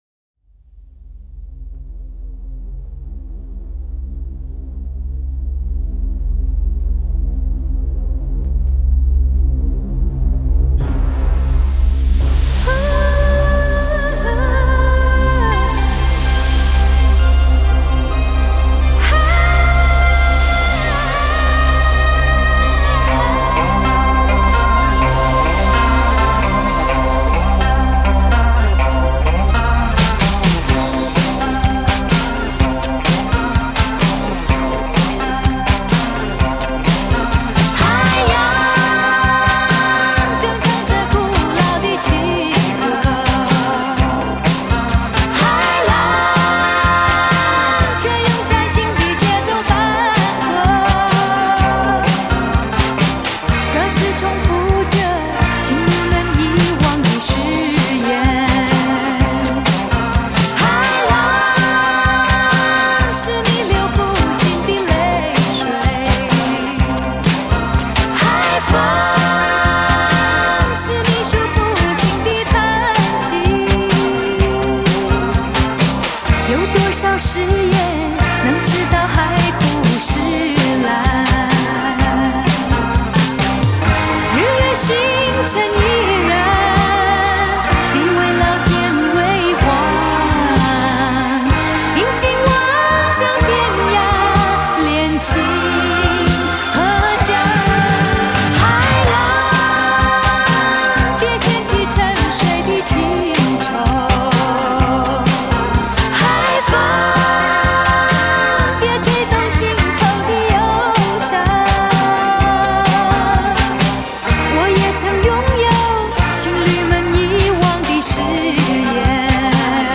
奔放狂野的